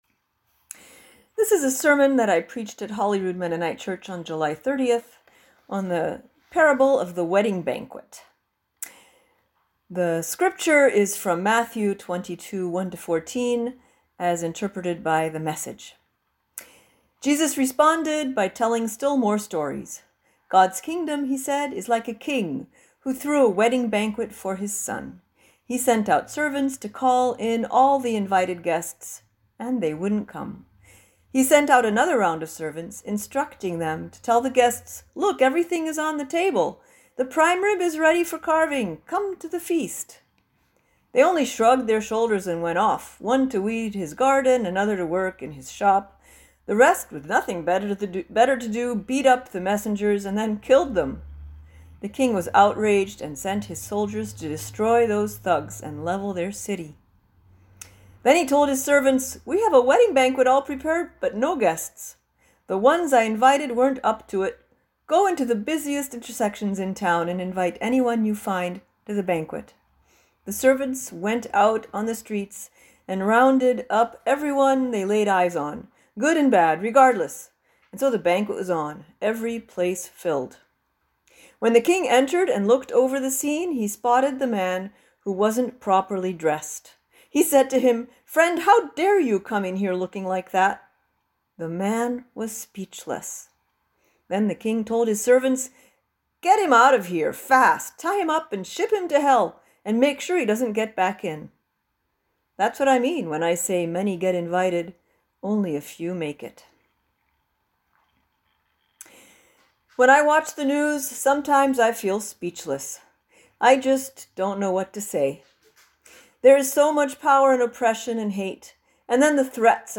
In this sermon, one of our two Intentional Interim Pastors shares a reflection about the Parable of the Wedding Banquet, as told in the book of Matthew 22:1-14.